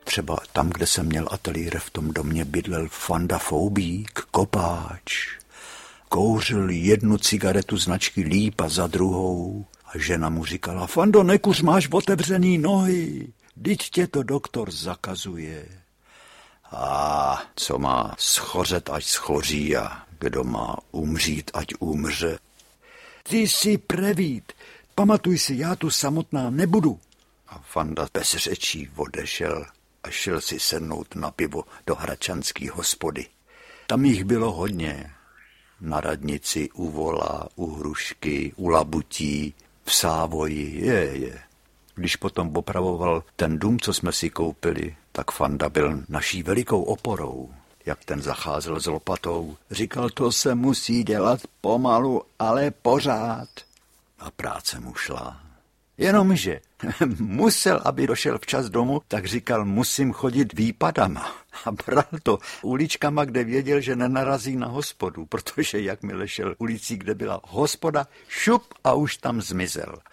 Ani mráčku na obláčku audiokniha
Vyprávění Jiřího Anderleho z rozhlasového cyklu Láska za lásku.
Ukázka z knihy